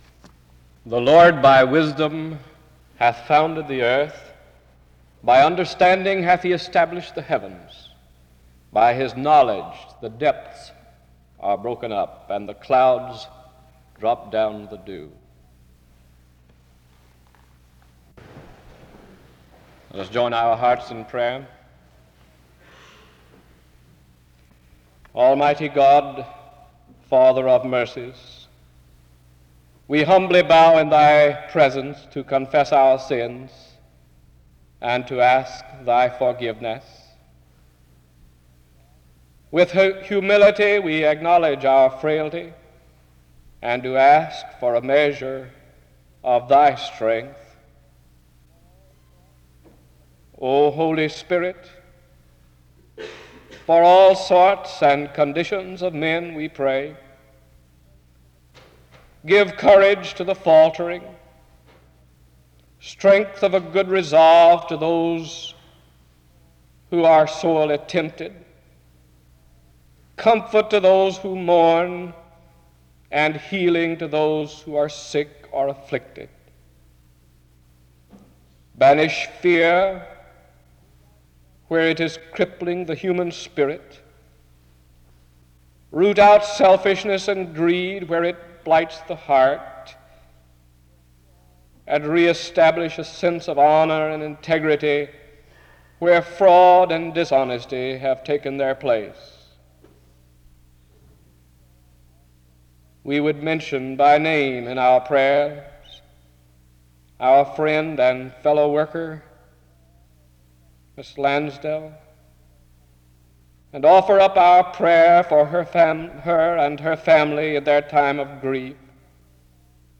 The service begins with the reading of Proverbs 3:19-20 (00:00-00:18) and prayer (00:19-03:30).
Location Wake Forest (N.C.)
His message focuses on four secrets of God in our world which we may often take for granted: the secret of the star, displaying God’s order (06:28-12:17), the secret of the seed, displaying God’s variety (12:18-14:23), the secret of the child, displaying God’s companionship with man (14:24-16:26), and the secret of the cross, displaying God’s power and wisdom (16:27-18:39). He ends with prayer (18:40-19:54).